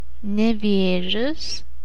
The Nevėžis (/nɪˈvɛʒɪs, nɛ-/; Lithuanian: [nʲɛˈvʲěːʑɪs]